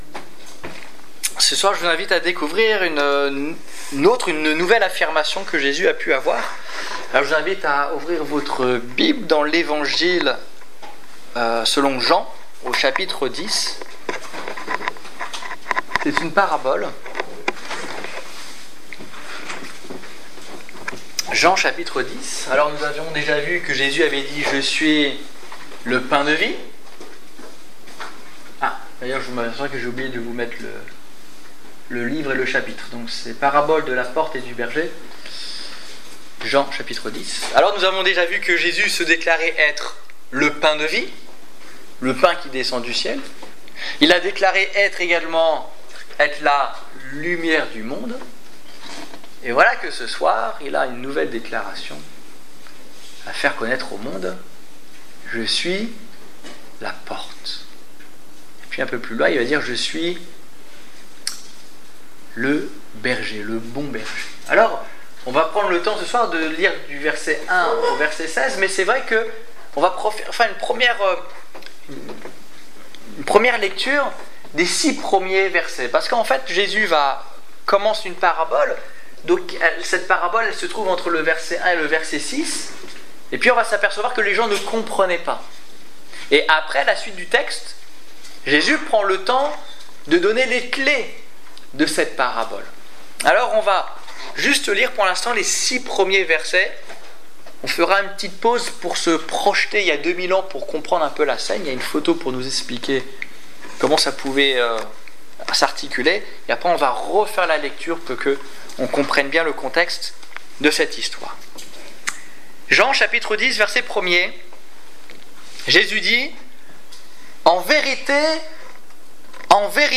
Je suis la porte, je suis le bon berger Détails Prédications - liste complète Évangélisation du 5 juin 2015 Ecoutez l'enregistrement de ce message à l'aide du lecteur